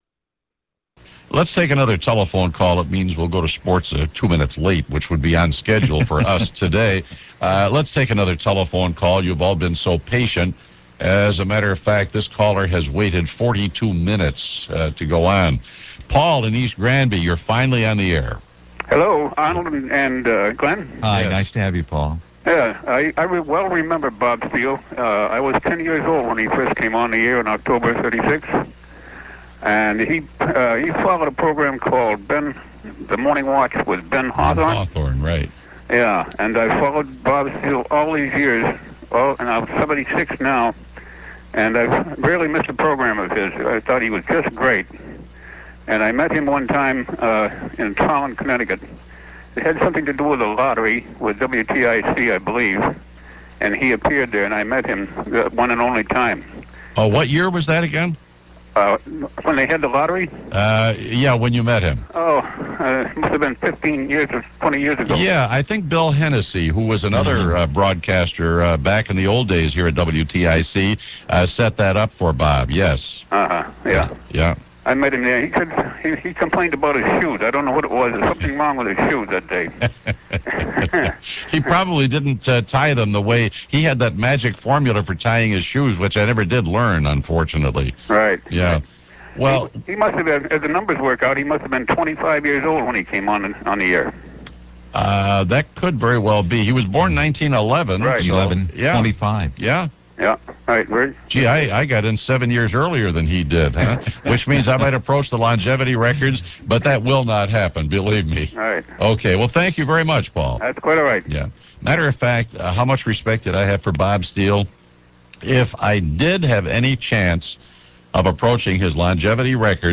I managed to air check many segments of the program, where many of Steele’s faithful listeners phoned in their remembrances: (Listener 1),